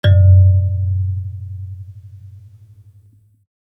kalimba_bass-F#1-pp.wav